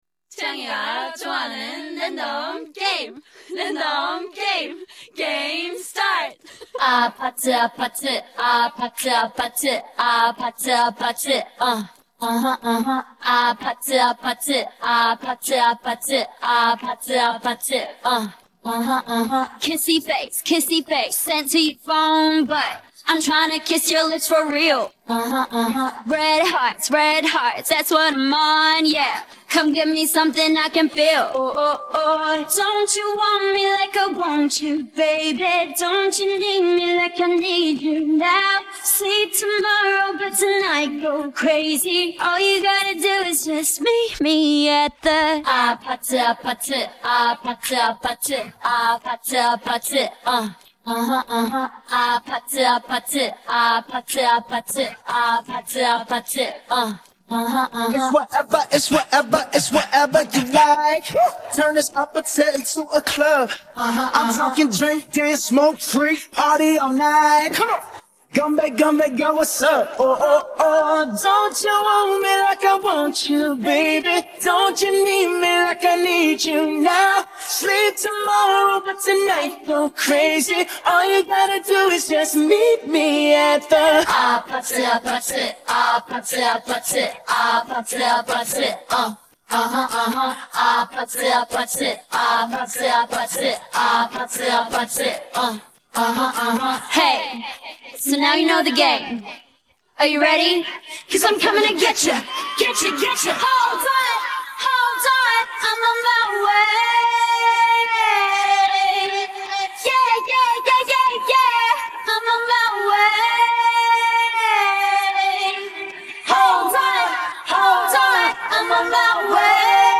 Partie vocale